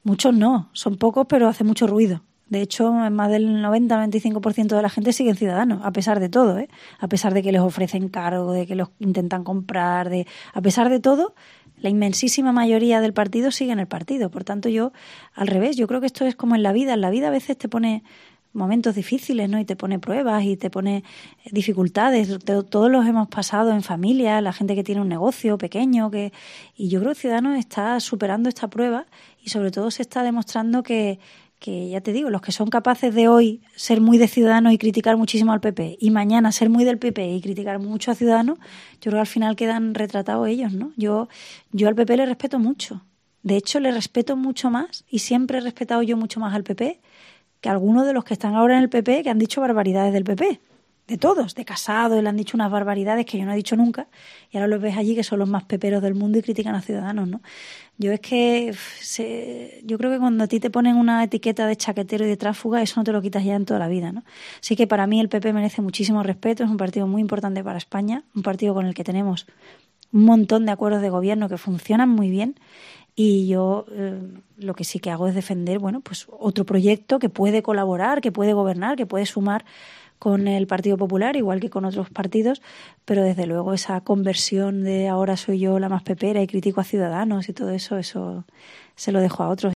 Inés Arrimadas ha concedido este sábado una entrevista a la cadena COPE en Extremadura tras su visita a la región, en la que se ha reunido con el presidente de la Junta de Extremadura, Guillermo Fernández Vara, ha celebrado un desayuno informativo y ha participado en actos con afiliados y con el alcalde de Badajoz, Ignacio Gragera.